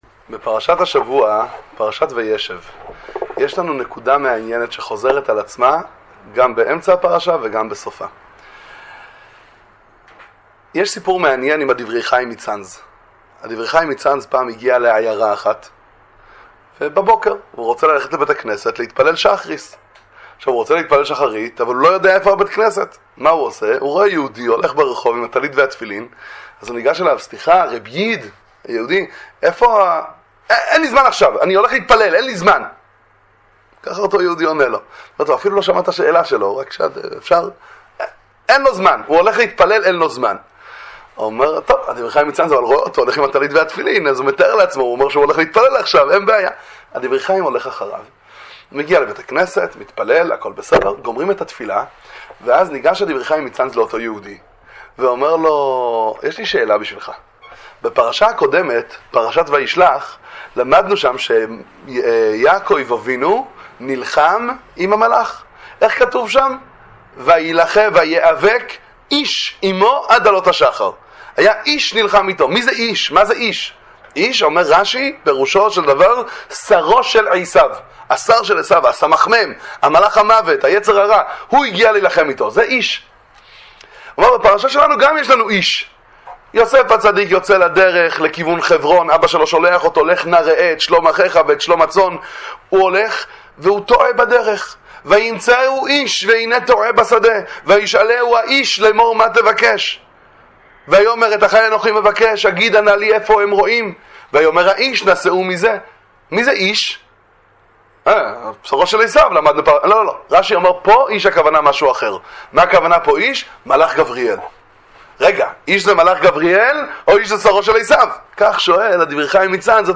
לעשות טוב… דבר תורה קצר לפרשת וישב